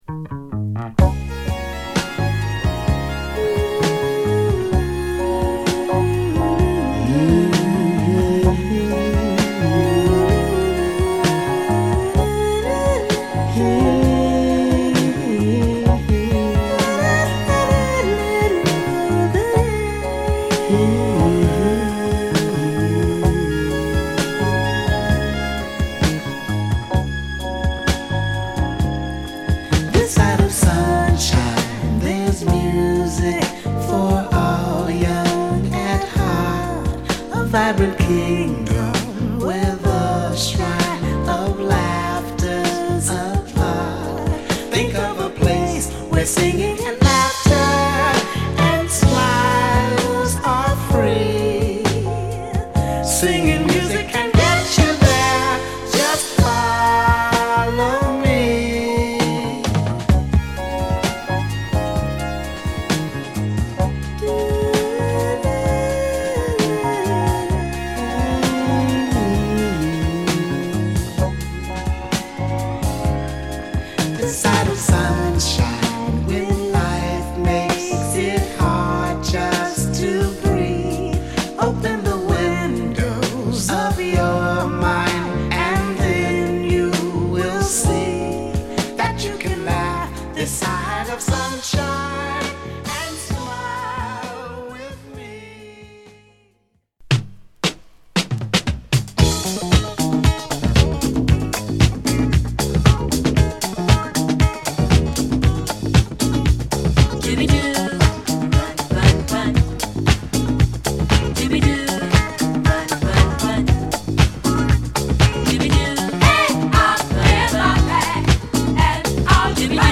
＊試聴はA1→A2→A3です。(別コピーからの音源です)